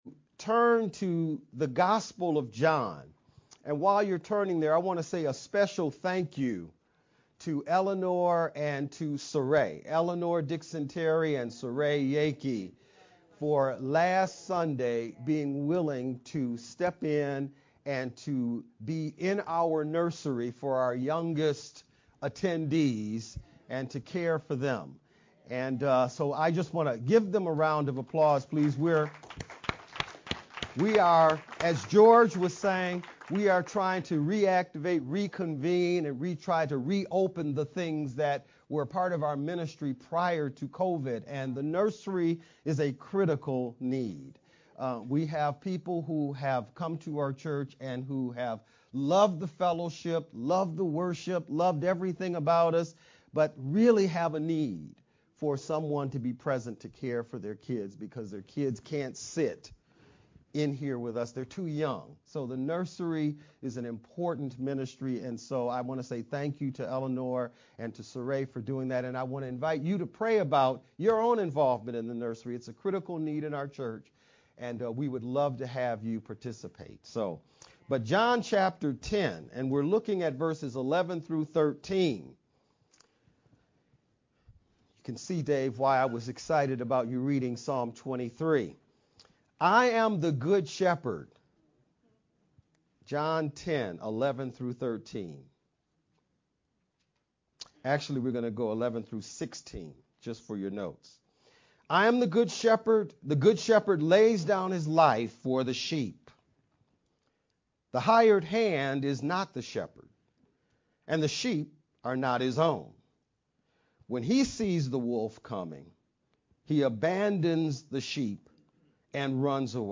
4-16-VBCC-Sermon-edited-sermon-only-Mp3-CD.mp3